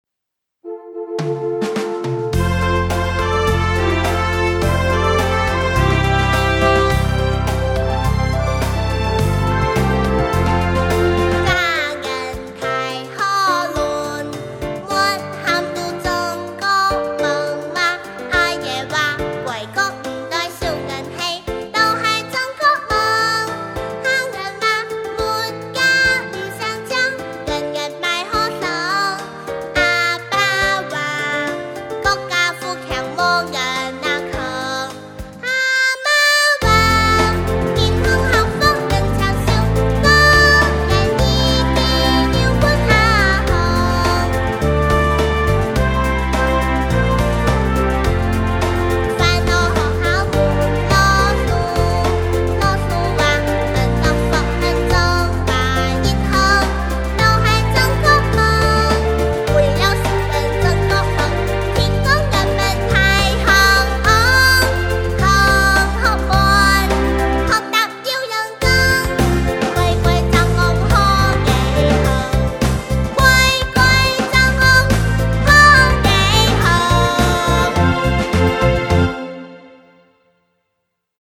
开平民歌集